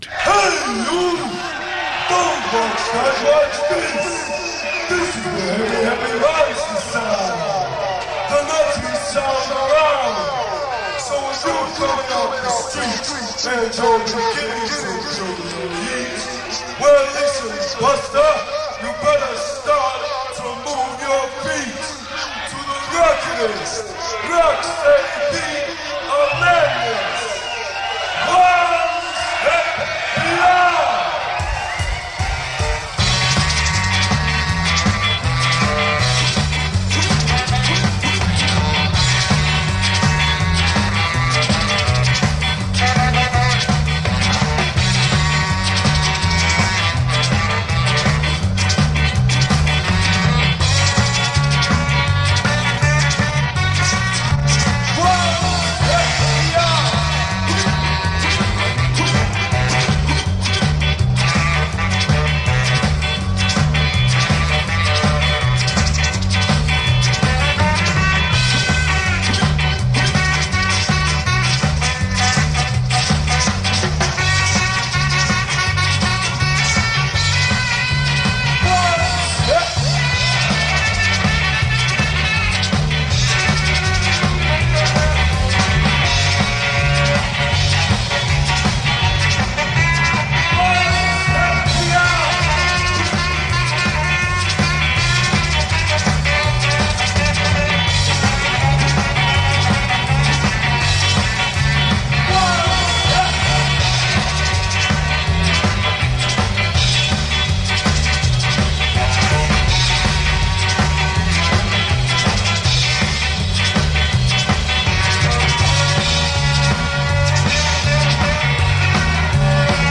The Great Ska Revival.
two-tone ska revival
a breath of fresh Ska.